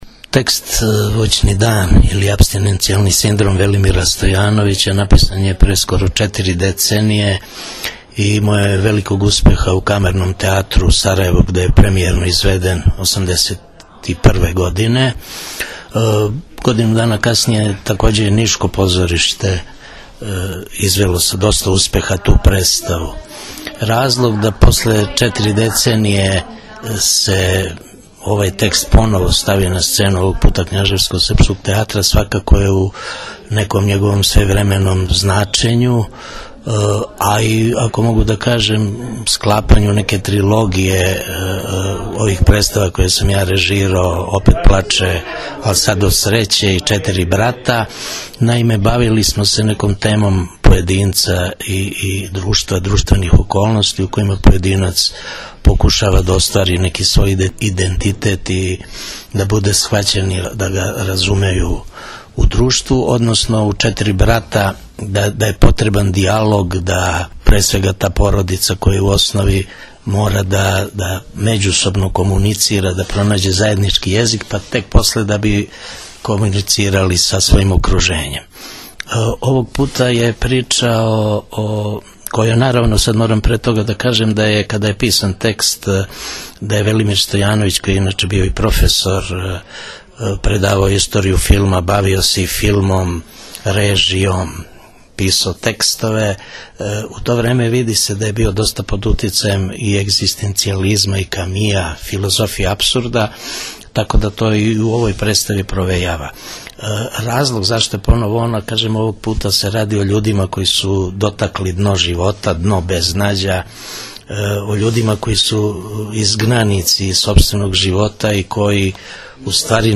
краћи разговор